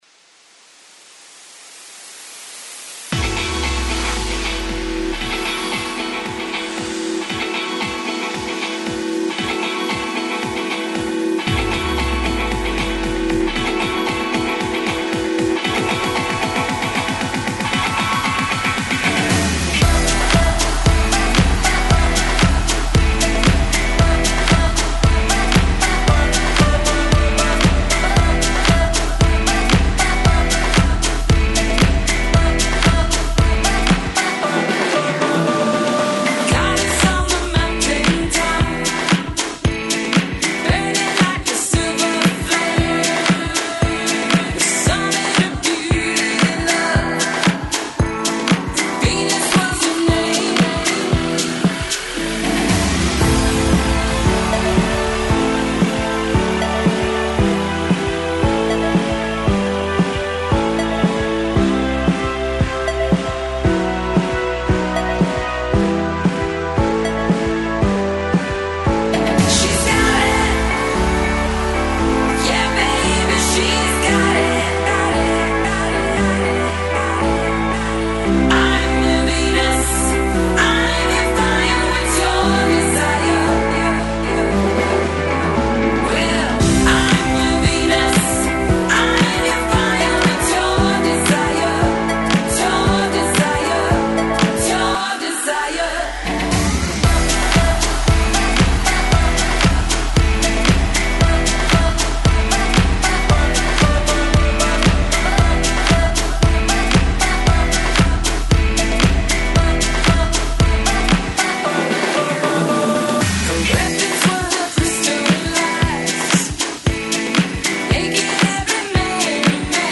Dance-Eletronicas